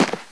Single Footstep